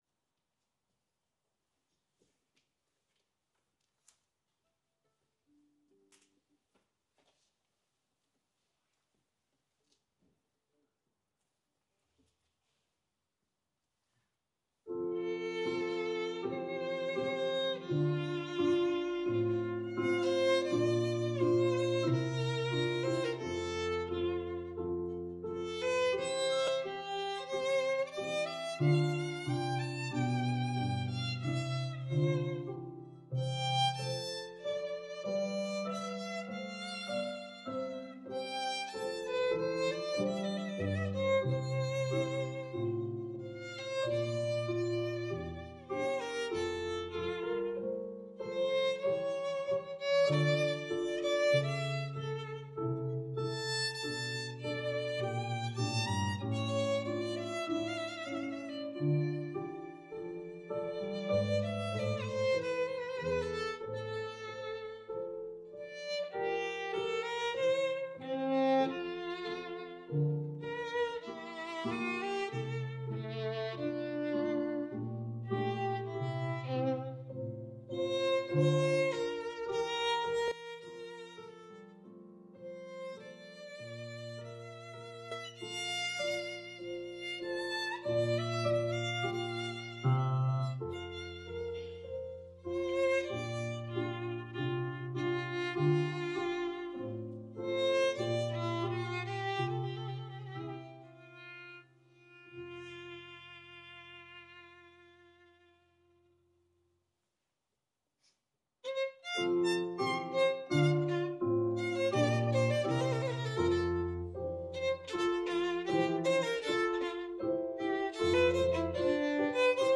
Audio-Gedanken-Lied-Predigt.mp3